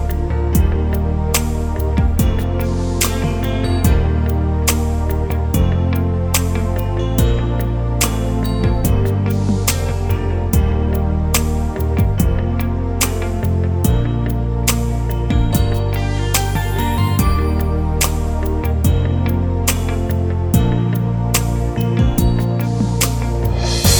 Pop (1980s)